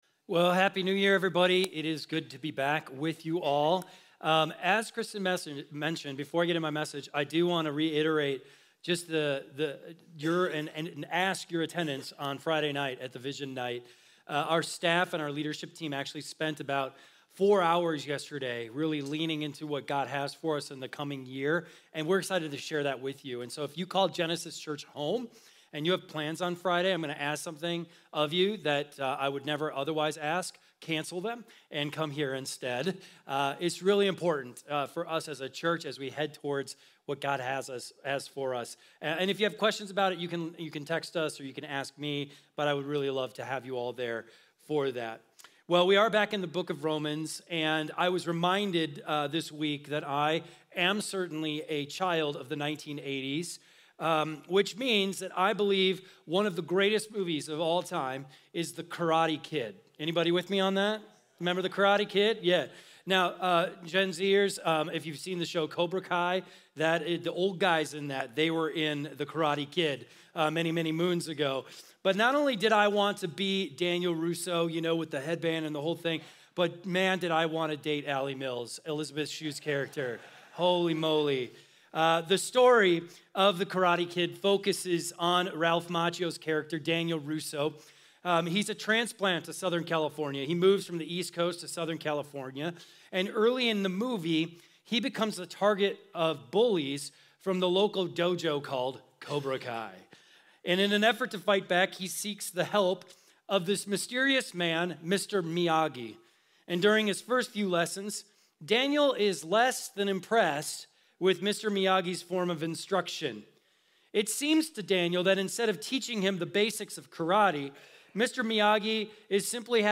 keyboard_arrow_left Sermons / Romans Series Download MP3